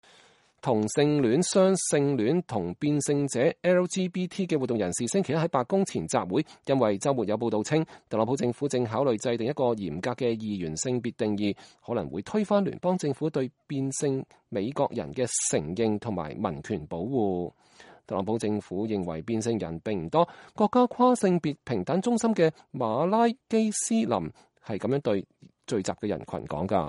2018年10月22日，美國國家跨性別平等中心和人權運動組織在華盛頓白宮前舉行集會。